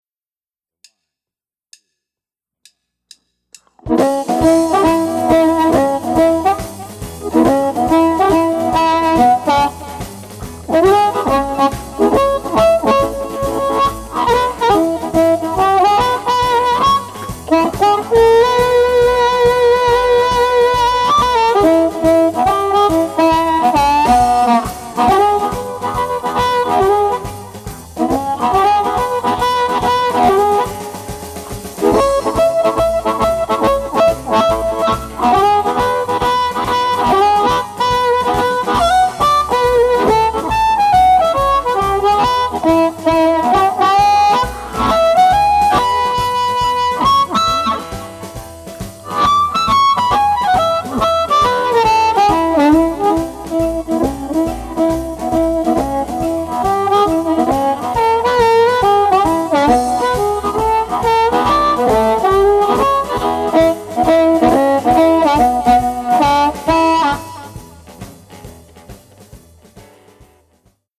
Amp Reviews | Blues Harmonica
Download the review sheet for each amp and the recorded mp3 files to compare the amps to make your choice of favorite amp.